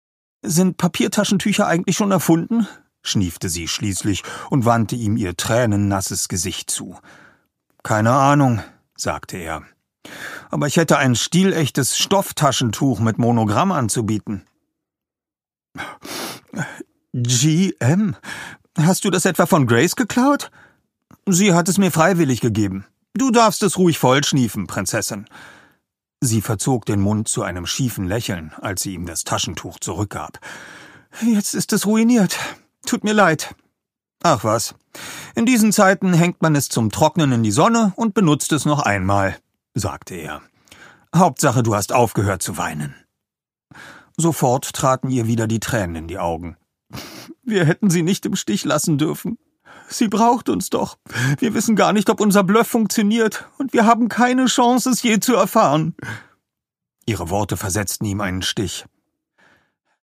Die Edelstein-Trilogie, Band 1 (Ungekürzte Lesung)
Der Name de Villiers wird in Übereinstimmung mit Kerstin Gier in diesem Hörbuch korrekt englisch ausgesprochen, da es sich zwar ursprünglich um ein französisches Geschlecht handelte, der englische Zweig der Familie aber mittlerweile englisch ausgesprochen wird.